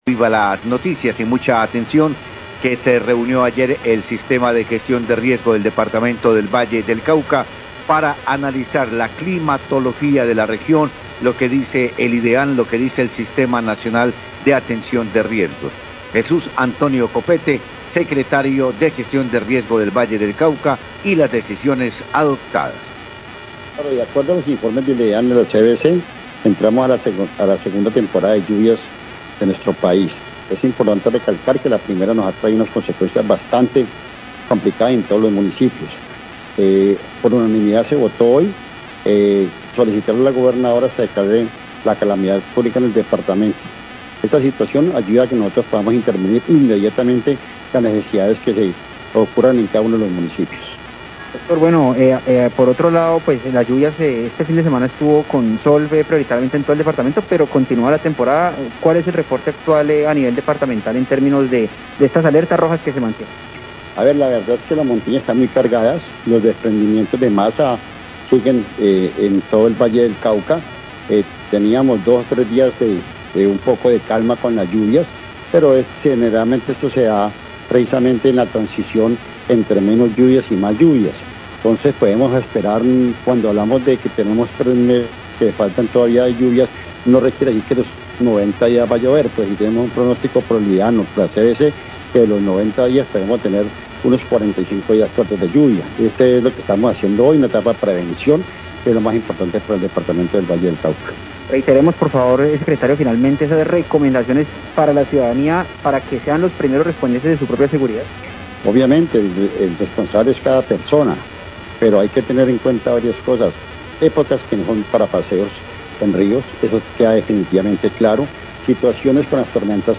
Radio
Secretario de Gestión de Riesgo del Valle, Jesús Antonio Copete, manifestó que soliticará a la gobernadora del Valle que declare la calamidad pública en el departamente debido a que inició la temporada de lluvias. Indicó que según datos de la CVC durante los 3 meses se podrían presentar hasta 45 días de lluvias.